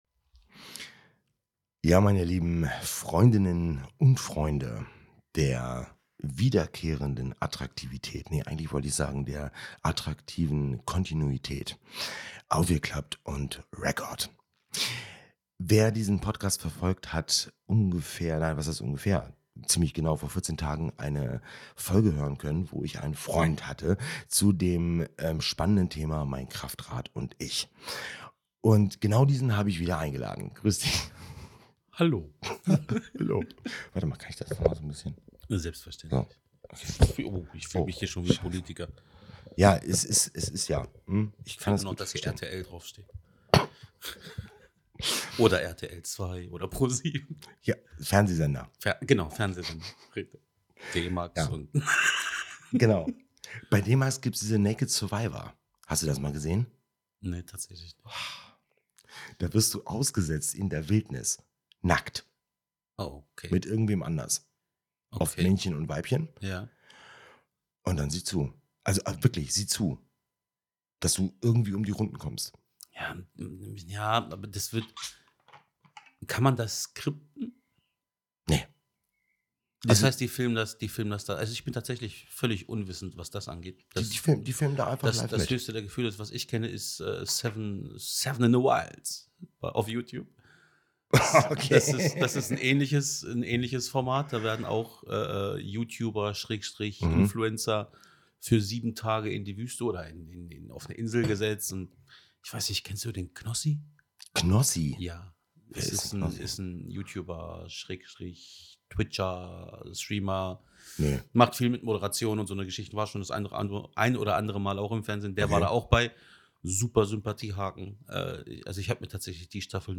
Instinktiveswissen als Auftakt in den Deep Dive der Intuition . zu Gast, wieder ein guter Freund, welcher bereits zweimal zu hören war.